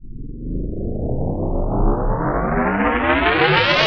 SCIFI_Up_04_mono.wav